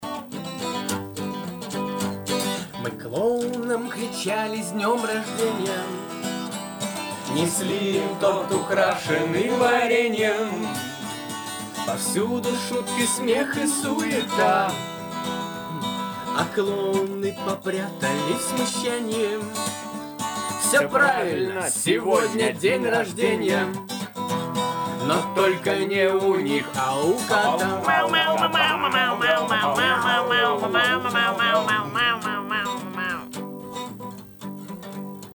Треки, исполненные вживую в Доме радио: